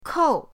kou4.mp3